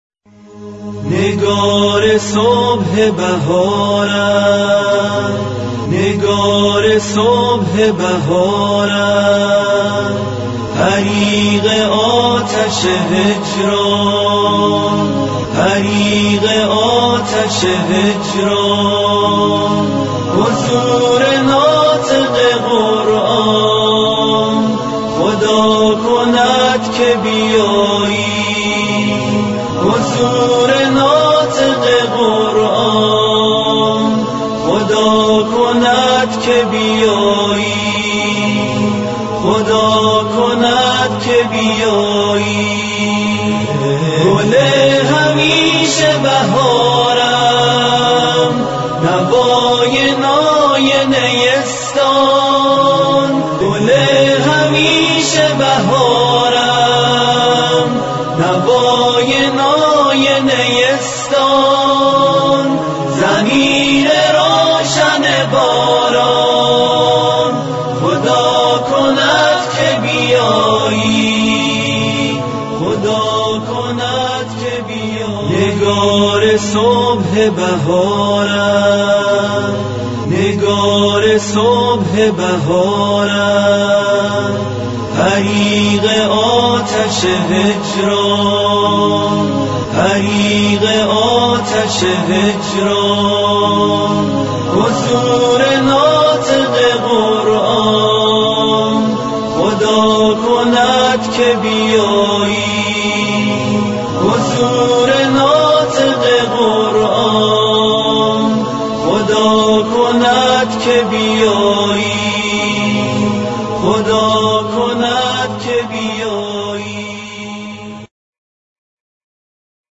همخوانی مهدوی